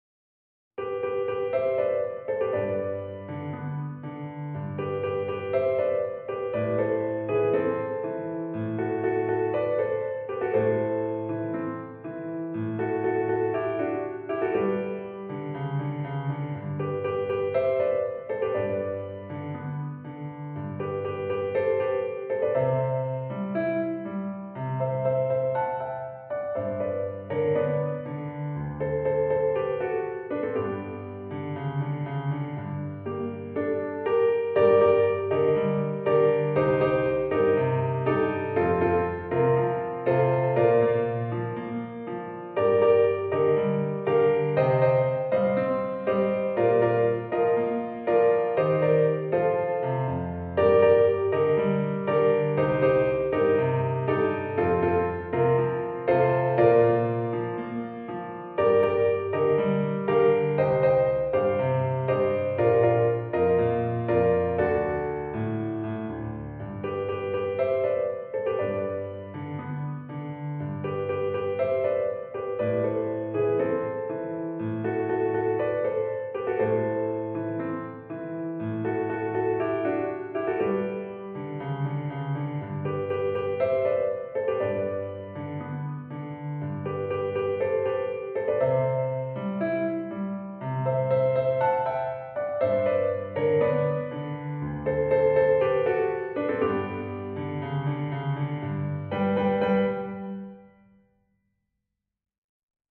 This is a piano arrangement
for piano